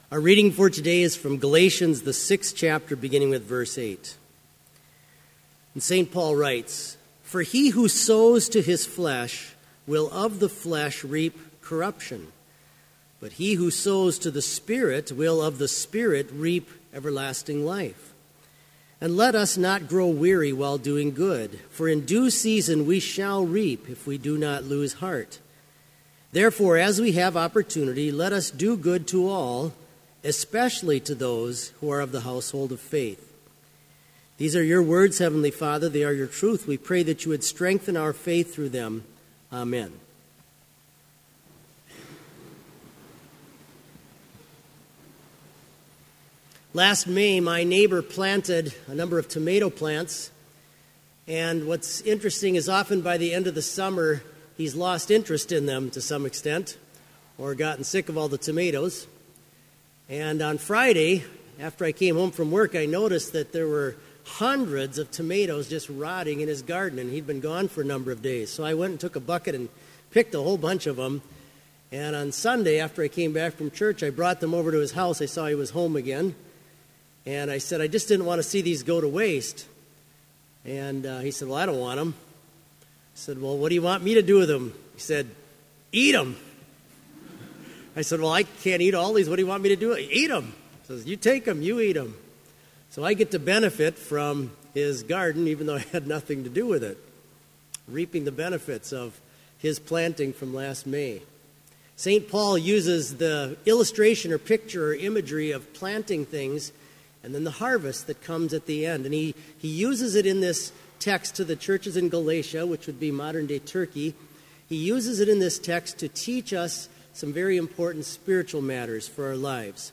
Complete service audio for Chapel - August 29, 2016